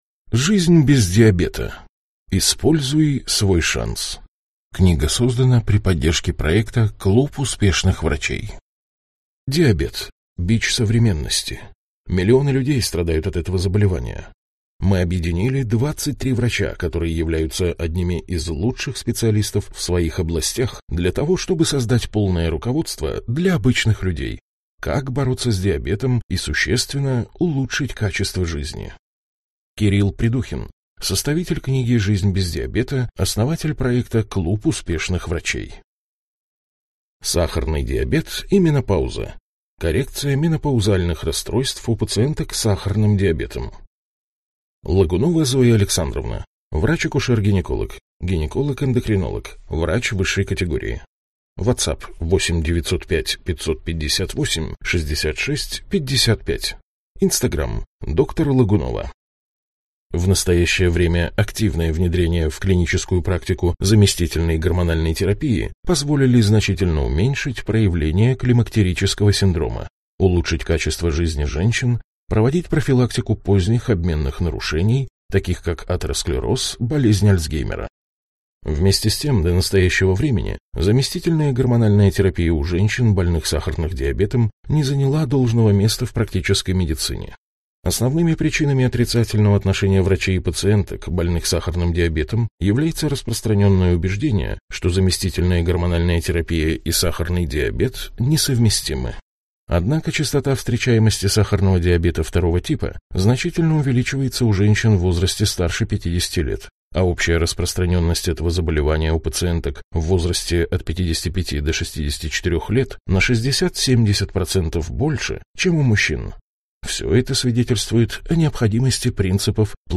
Аудиокнига Жизнь без диабета. Используй свой шанс | Библиотека аудиокниг